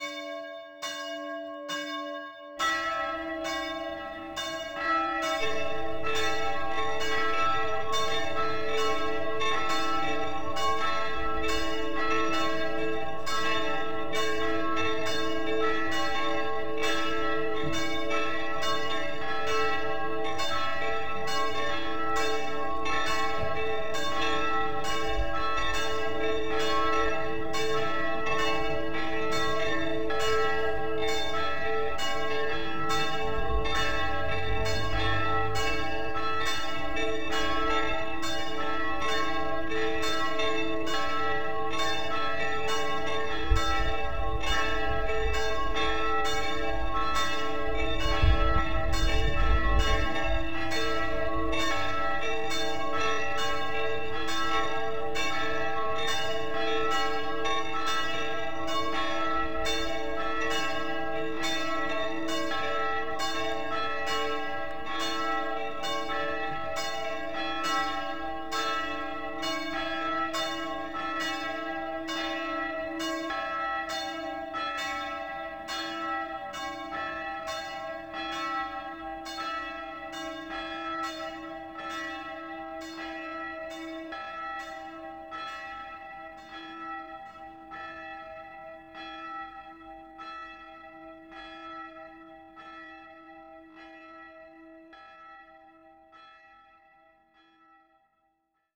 Simulation des Glockenklangs hier zu hören:
Simulation-Marbacher-Glocke.wav